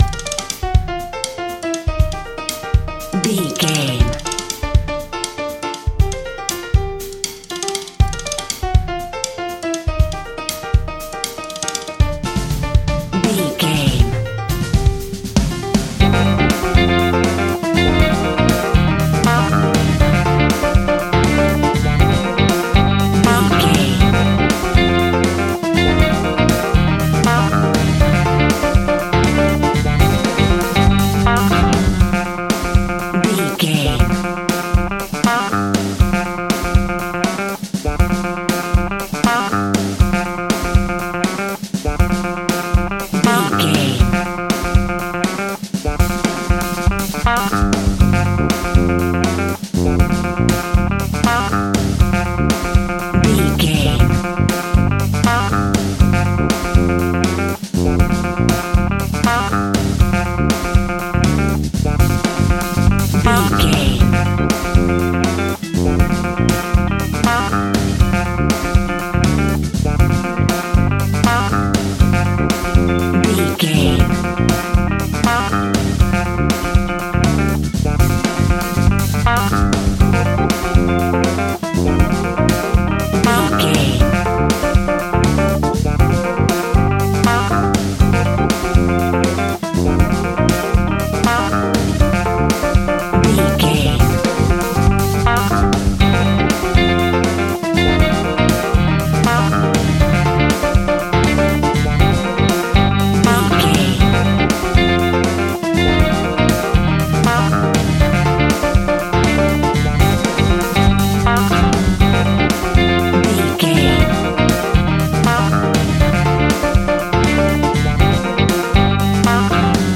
Aeolian/Minor
latin
uptempo
bass guitar
brass
saxophone
trumpet
fender rhodes
clavinet